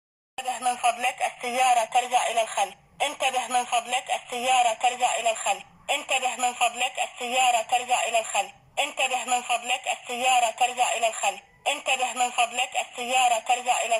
حسناً، ما سمِعته أعلاه هو الصوت الذي يصدر عند رجوع السيارات إلى الخلف وأقصد تحديداً السيارات العمومية (التاكسي).
car.mp3